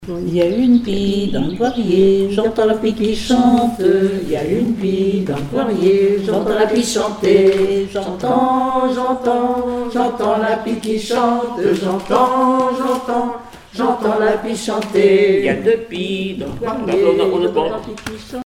Couplets à danser
enfantine : berceuse
Comptines et formulettes enfantines
Pièce musicale inédite